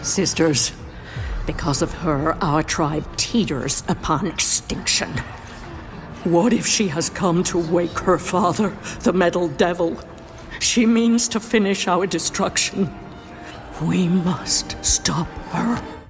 American Adult ● Senior
Videogame